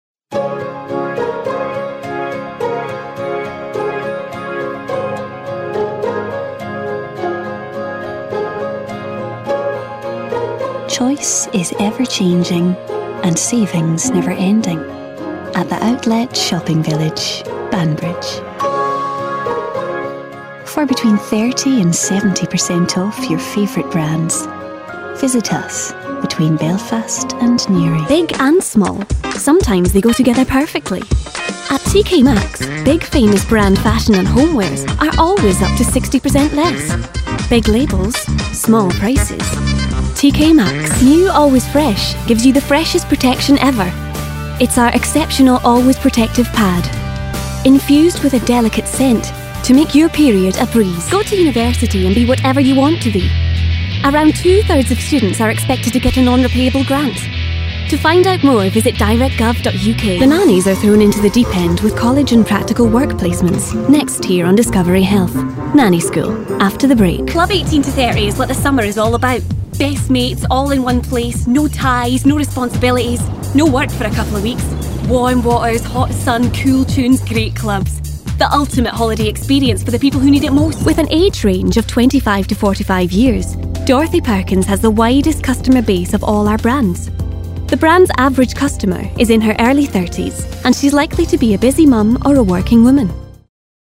SCOTS. Smooth and calming to upbeat irreverence. Personable lass. A natural VO. Avid Muso.
Her accents range from Scottish East Coast, Edinburgh and Highlands to RP and Irish, and voice age ranging from teens to late 20s.